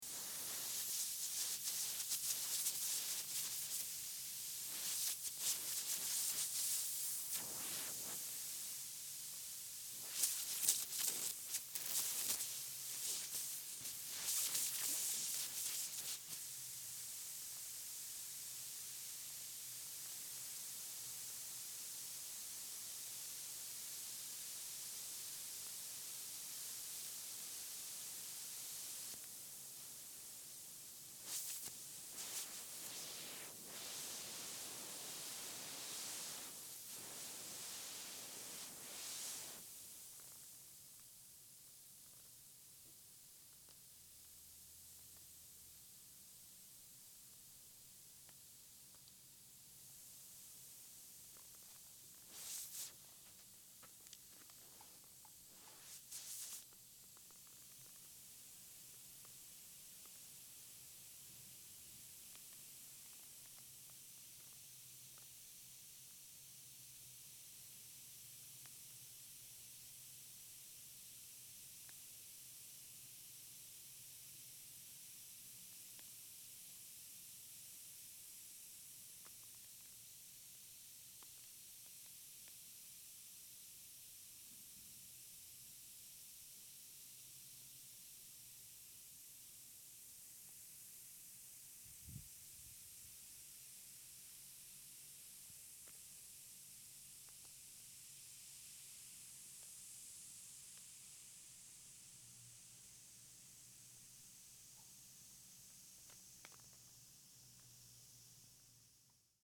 Звуки спускающего колеса от прокола и движение машины на спущенном колесе
prokol-shyny-1.mp3